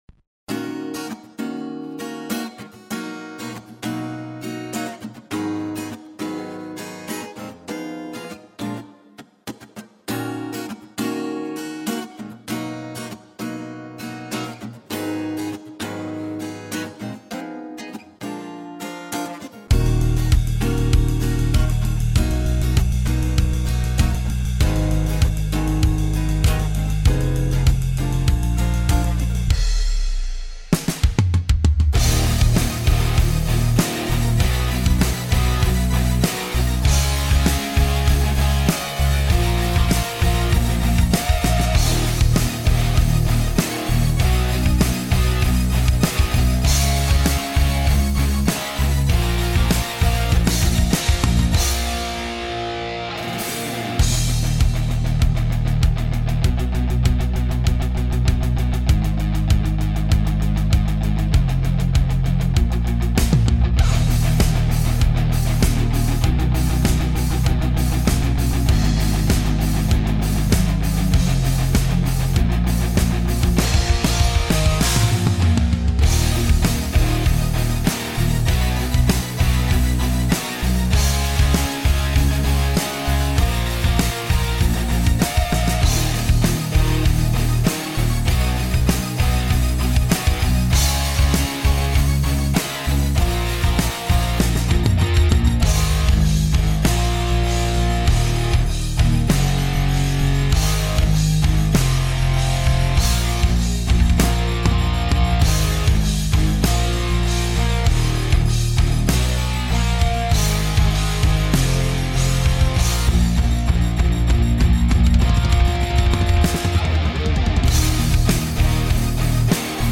минусовка версия 248834